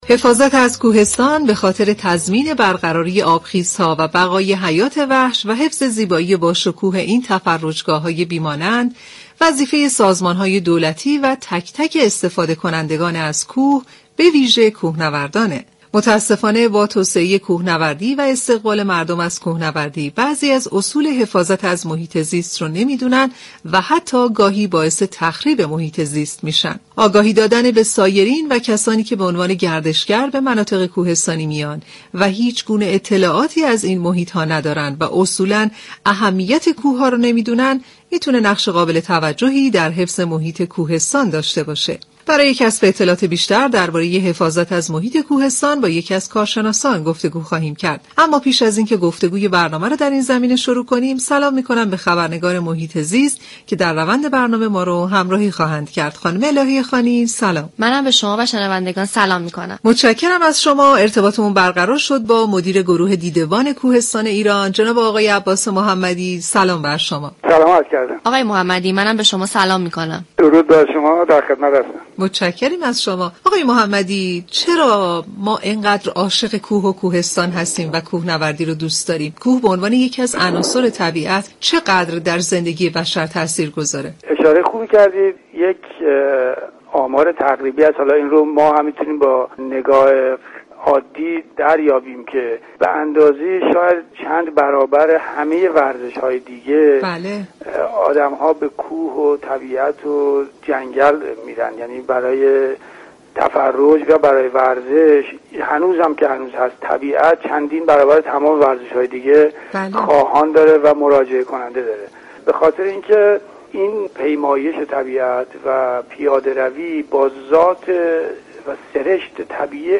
این گفت و گو را می شنویم :